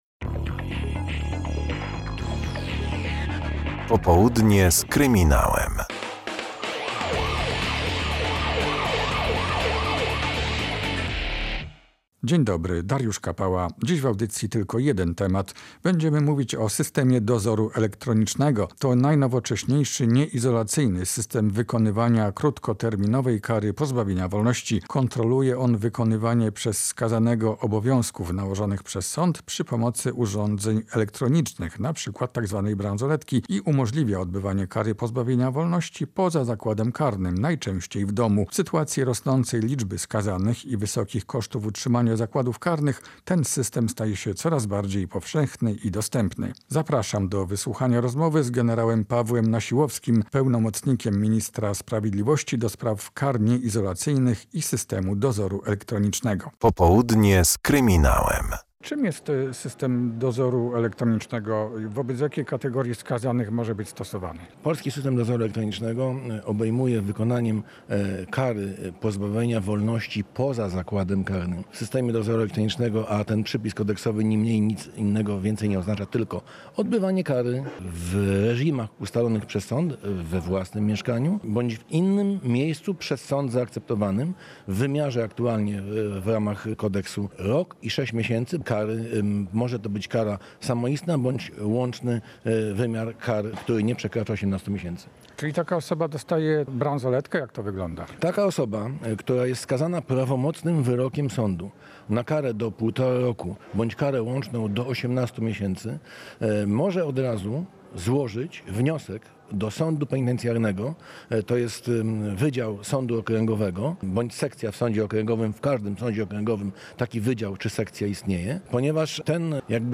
W audycji rozmowa z generałem Pawłem Nasiłowskim, pełnomocnikiem ministra sprawiedliwości do spraw kar nieizolacyjnych i systemu dozoru elektronicznego o jednym ze sposobów wykonywania krótkoterminowej kary pozbawienia wolności.